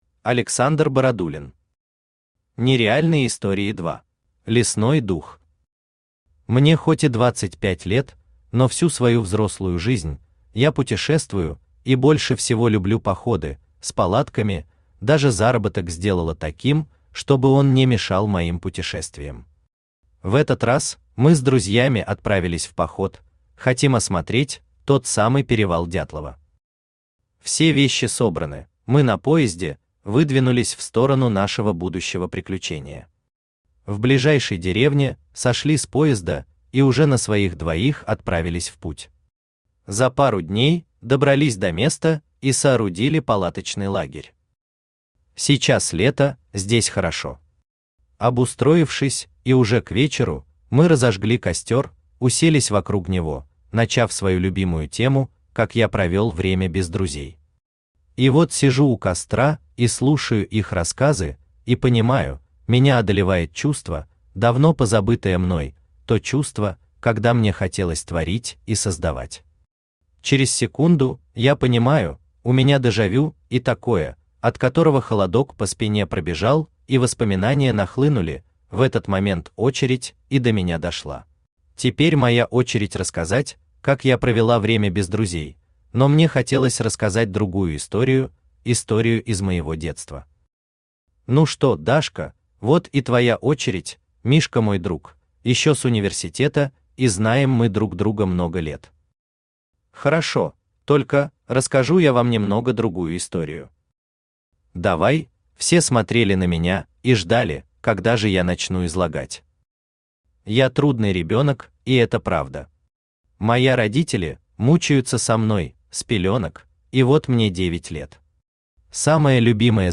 Аудиокнига Нереальные истории 2 | Библиотека аудиокниг
Aудиокнига Нереальные истории 2 Автор Александр Викторович Бородулин Читает аудиокнигу Авточтец ЛитРес.